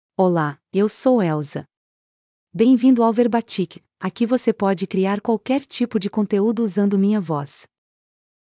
Elza is a female AI voice for Portuguese (Brazil).
Voice sample
Listen to Elza's female Portuguese voice.
Elza delivers clear pronunciation with authentic Brazil Portuguese intonation, making your content sound professionally produced.